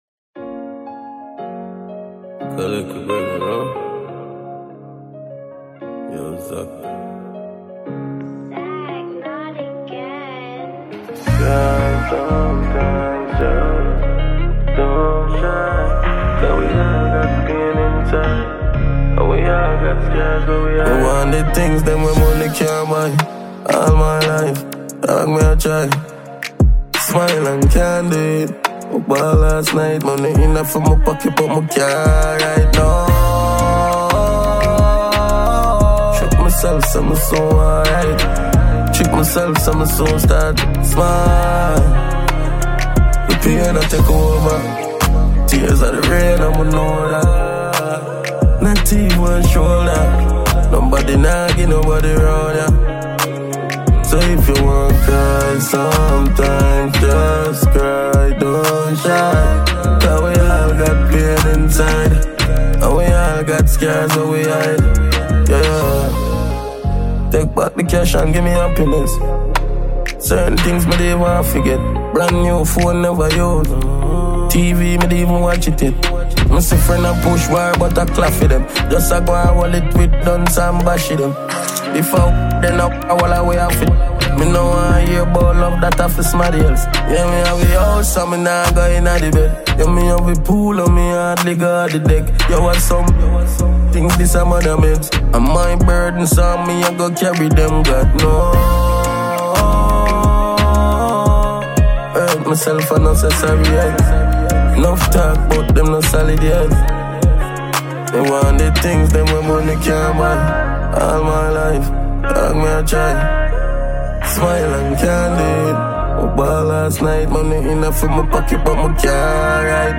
International Jamaican Reggae Dancehall Musician
a song featured another dancehall musician